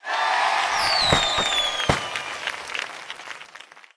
通关成功.mp3